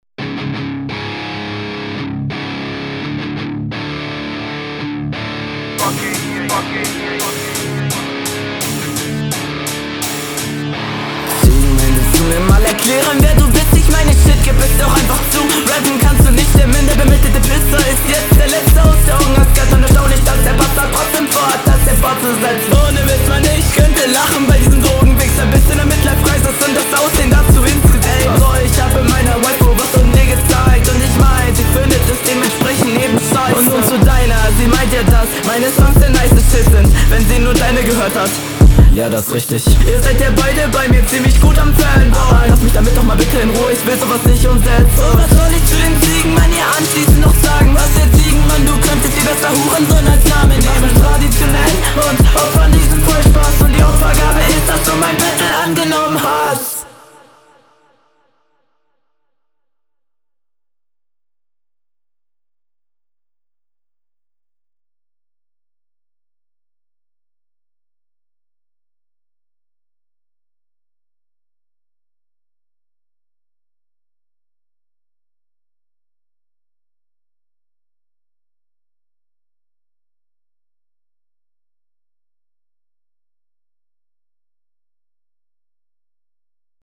Teils noch etwas unroutiniert aber das scheppert schon ziemlich.
Du hast einen super dopen Stimmeinsatz, das klingt einfach super stylisch und ausgereift.
Mutiger Beatpick, solchen Instrumentals wird man aber nur schwer gerecht.